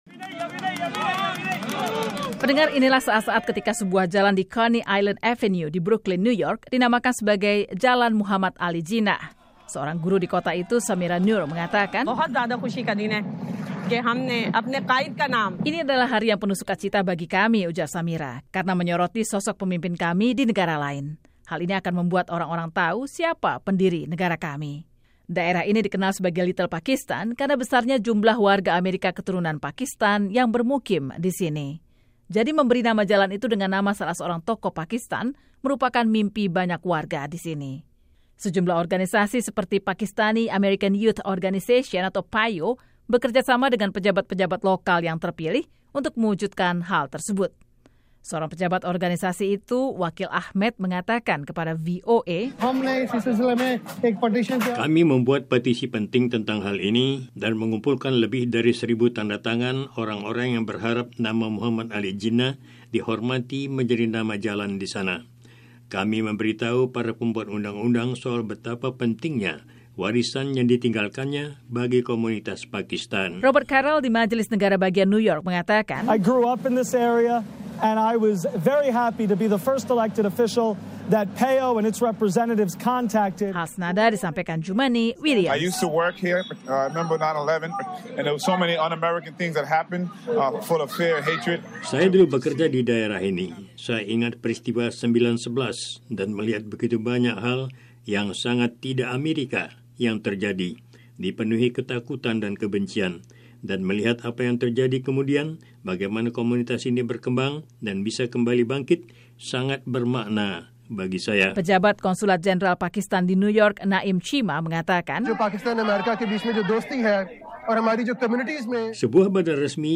Amerika sudah sejak lama memiliki tradisi untuk mengakui keberadaan tokoh-tokoh negara lain dengan memberi nama jalan menggunakan nama mereka. Satu sudut jalan di Coney Island Avenue, Brooklyn, New York, kini diberi nama tokoh modern Pakistan, yaitu Muhammad Ali Jinnah. Berikut laporannya.